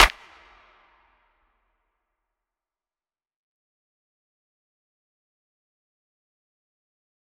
DMV3_Clap 12.wav